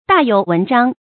大有文章 注音： ㄉㄚˋ ㄧㄡˇ ㄨㄣˊ ㄓㄤ 讀音讀法： 意思解釋： 指話語、文章、或已表露的現象之中；很有令人難以捉摸的意思或別的情況。